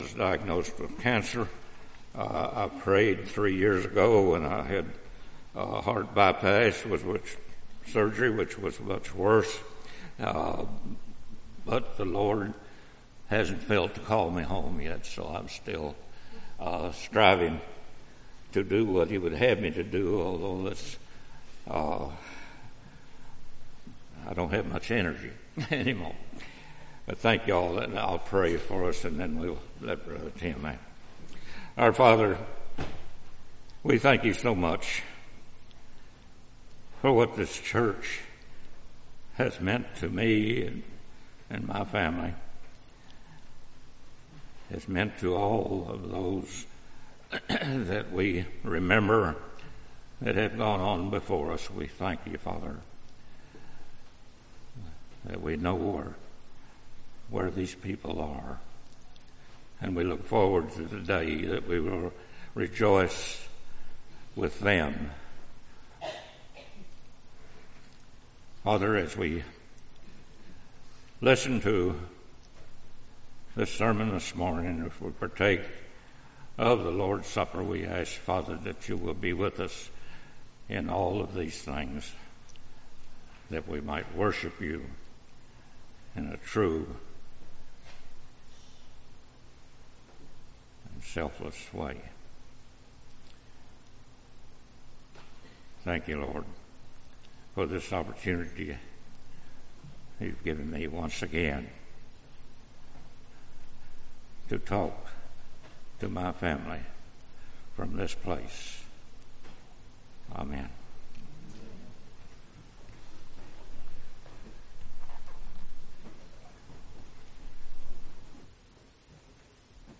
Sunday, October 7, 2018 (Sunday Morning Service)